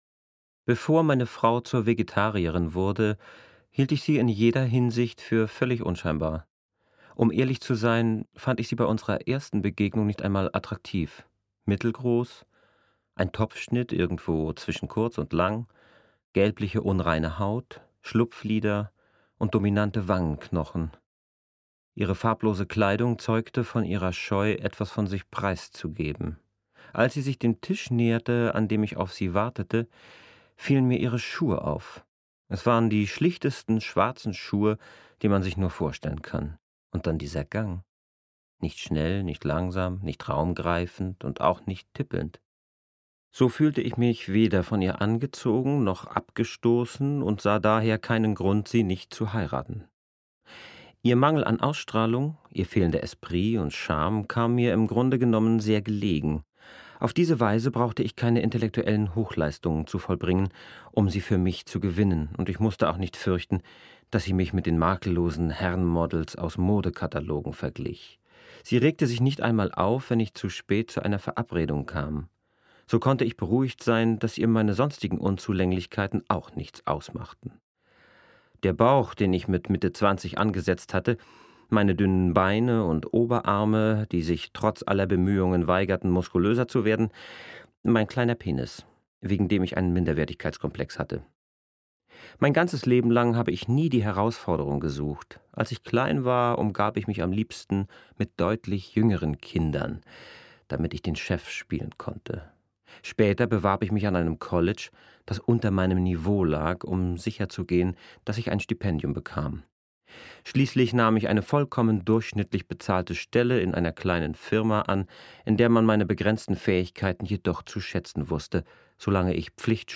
Auflage, Ungekürzte Ausgabe